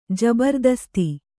♪ jabardasti